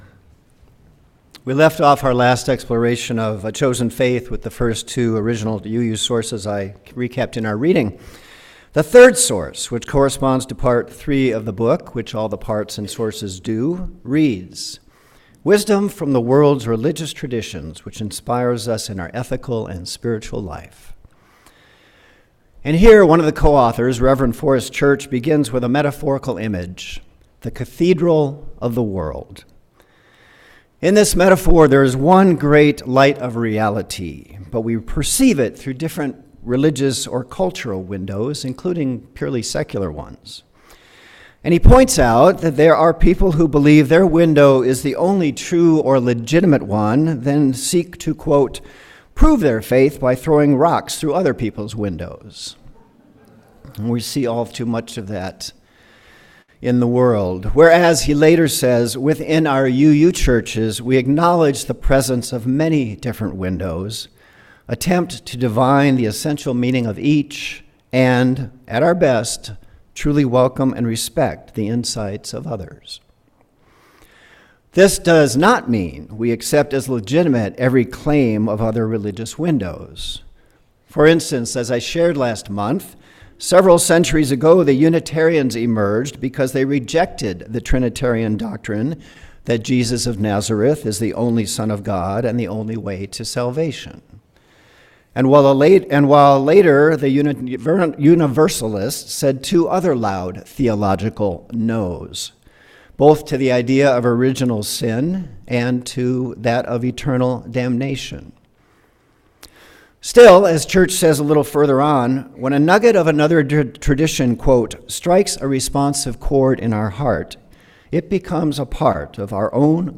Sermon Topic: Changes, Challenges, and Consciousness